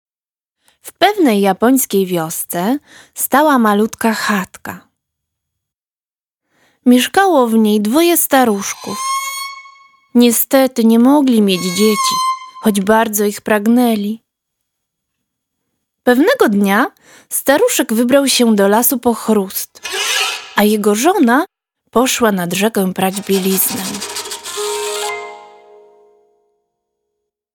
01. Narrator